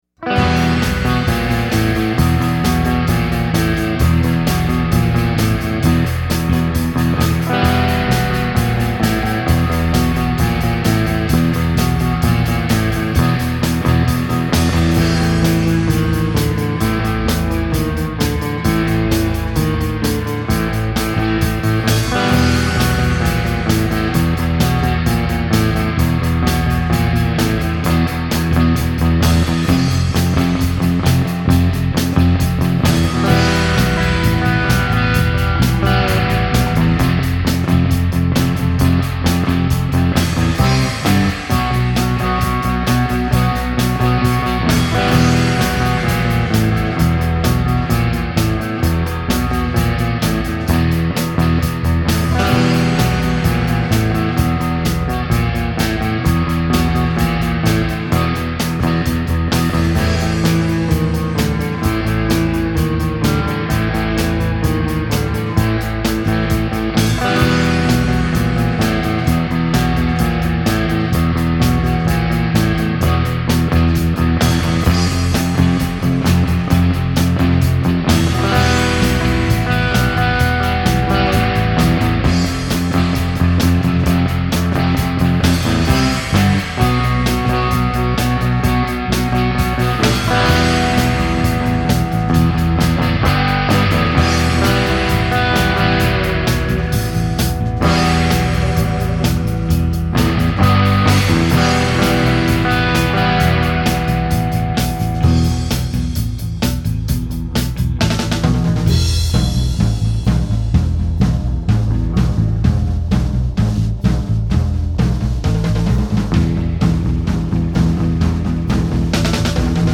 Rock
Instrumental Surf Rock